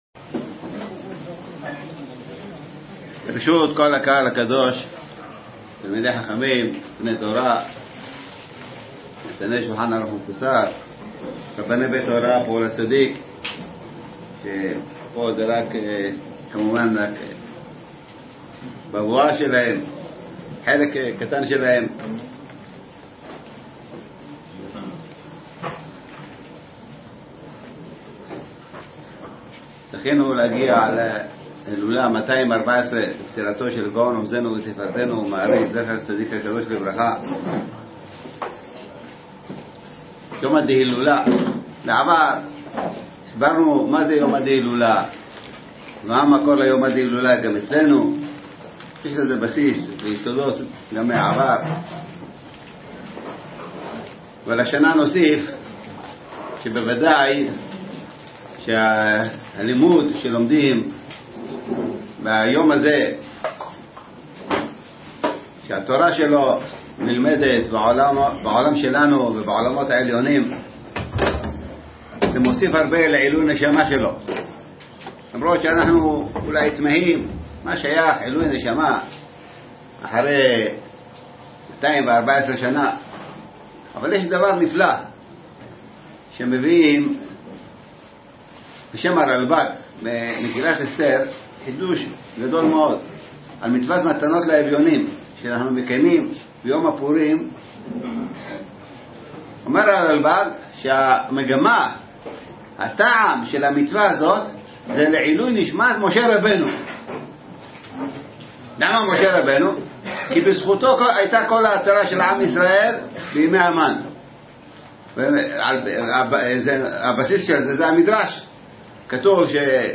חדש! דרשת מרן שליט"א - הילולת מהרי"ץ ה'תשע"ט - בני ברק ת"ו